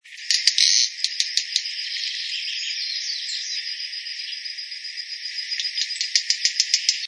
Bate-bico (Phleocryptes melanops)
Nome em Inglês: Wren-like Rushbird
Fase da vida: Adulto
Localidade ou área protegida: Reserva Ecológica Costanera Sur (RECS)
Condição: Selvagem
Certeza: Gravado Vocal